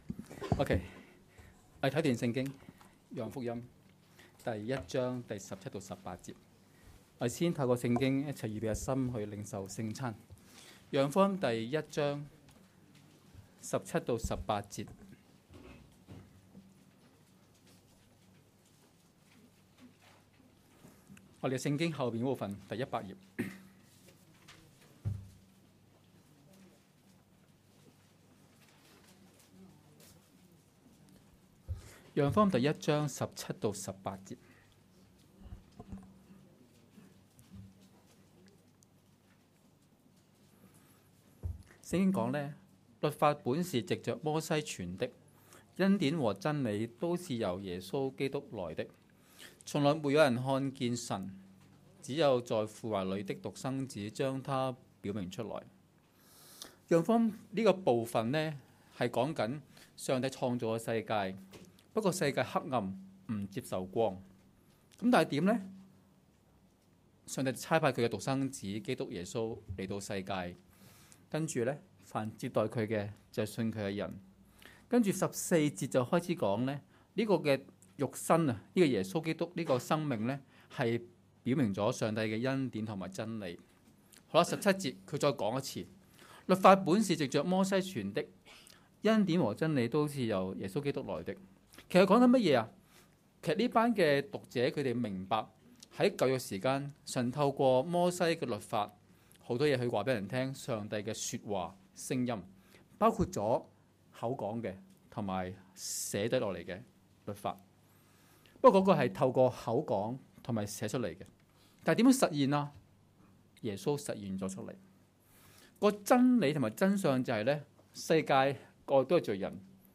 2016年6月4日及5日崇拜
講道：重遇生命中的朋友